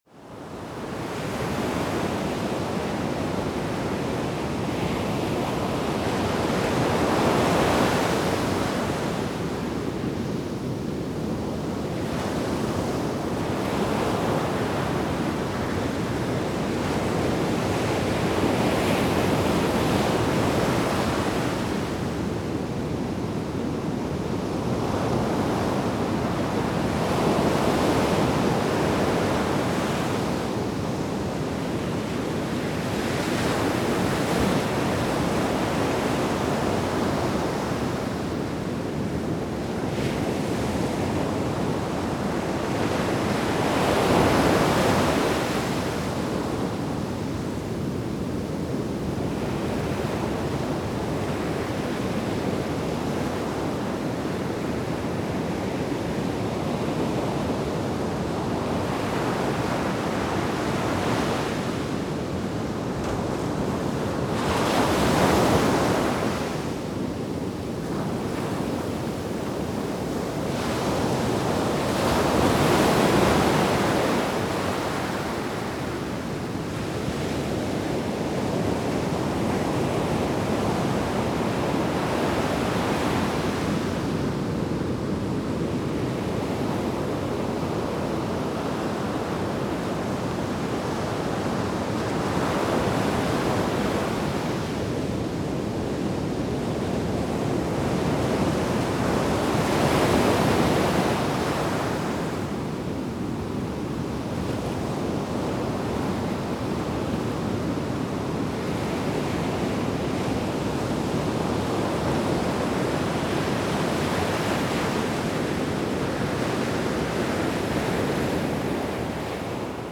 Звуки океана
Шум разбушевавшихся океанских волн